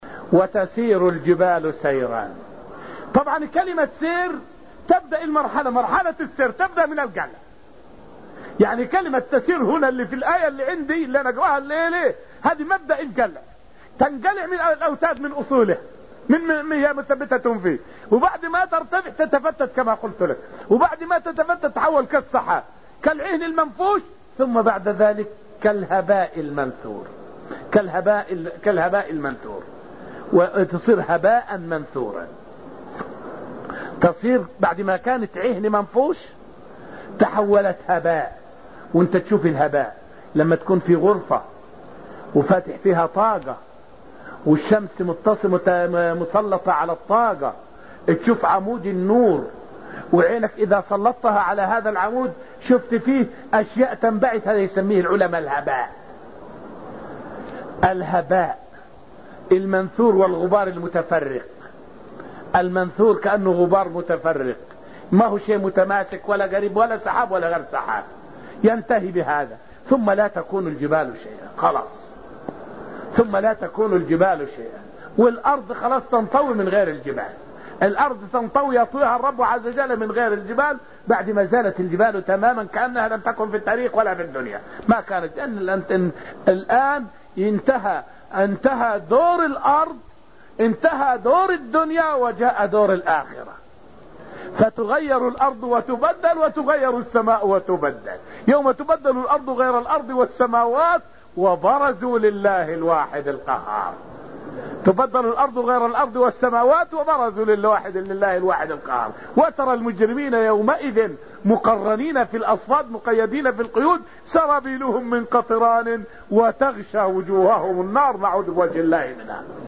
فائدة من الدرس الثاني من دروس تفسير سورة الطور والتي ألقيت في المسجد النبوي الشريف حول مراحل تغير مشهد الجبال يوم القيامة.